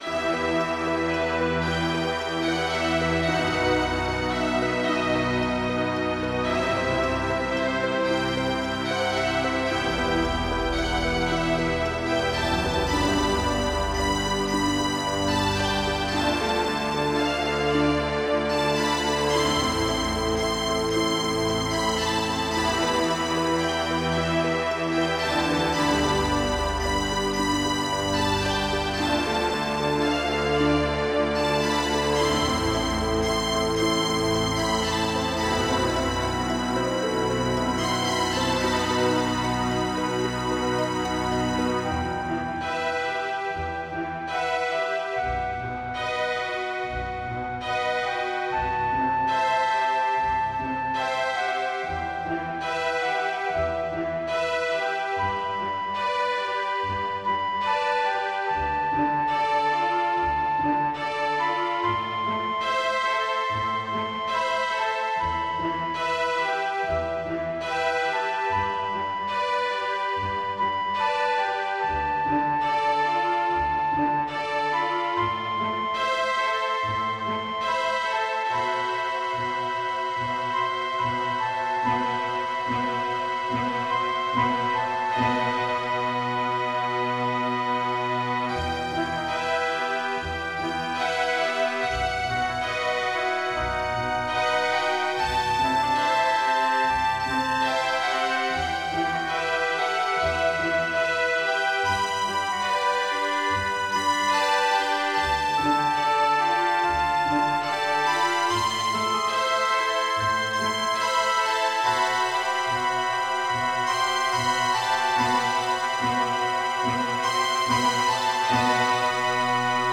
General MIDI